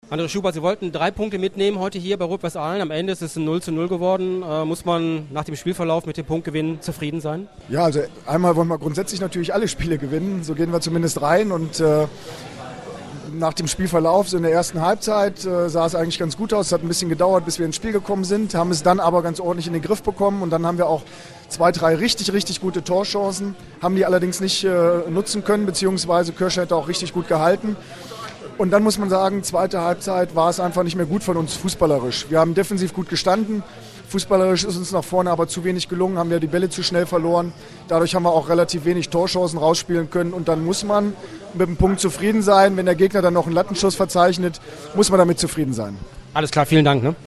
AUDIOKOMMENTAR